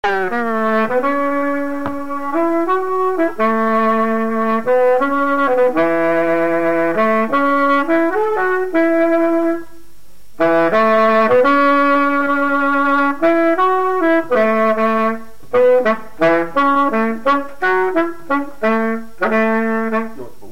Résumé instrumental
gestuel : à marcher
Pièce musicale inédite